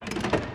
SFX_Motorcycle_Crane_Grab.wav